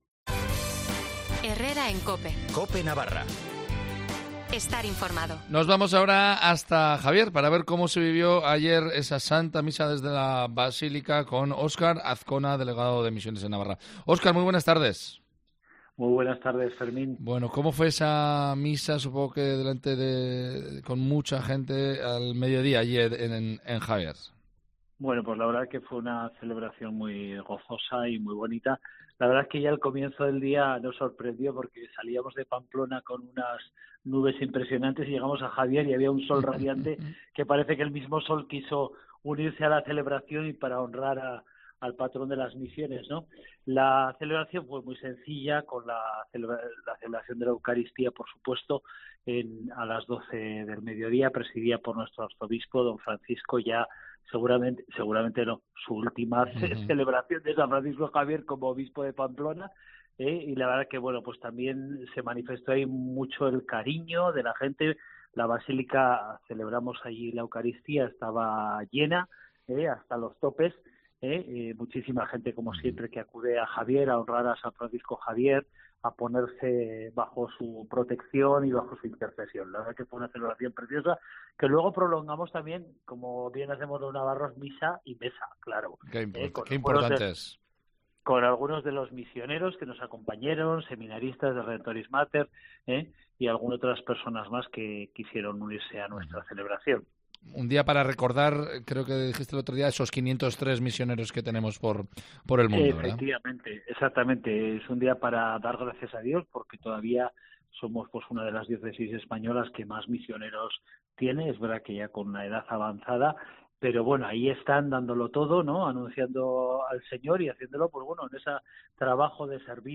Misa en la Basílica de Javier